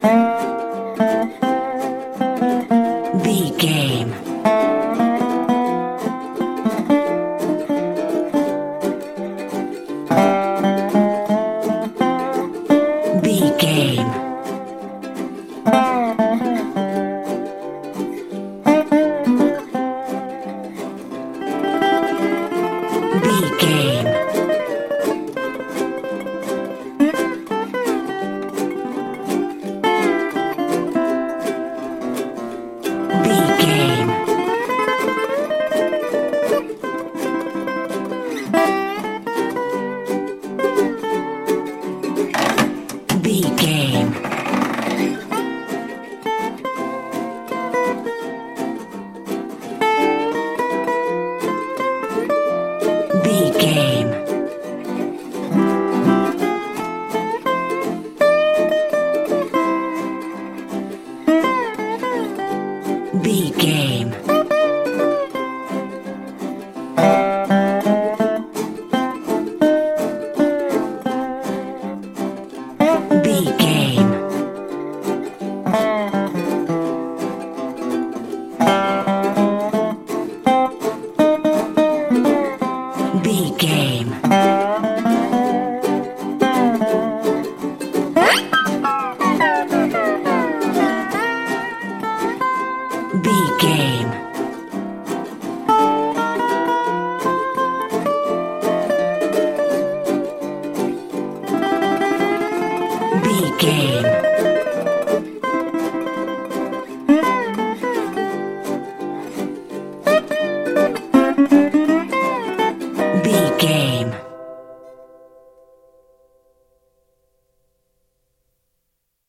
Ionian/Major
acoustic guitar
banjo
percussion
ukulele
slack key guitar